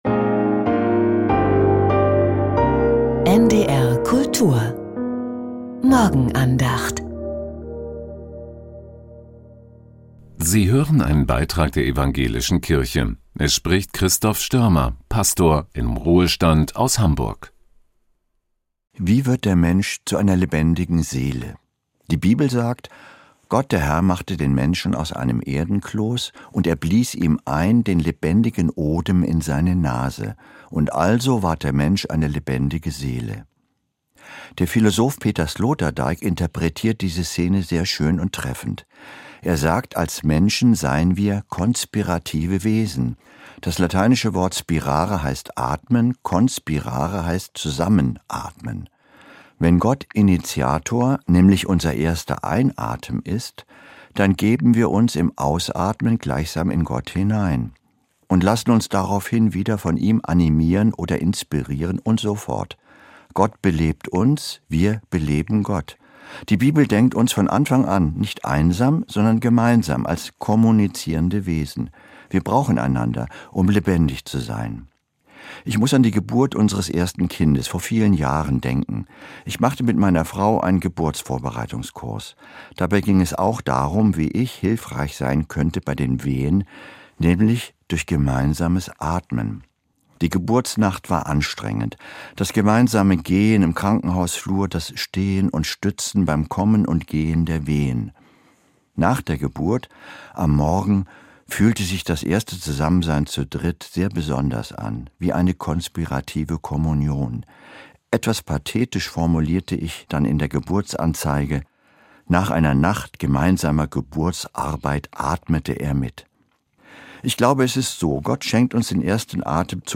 Lebendige Seele ~ Die Morgenandacht bei NDR Kultur Podcast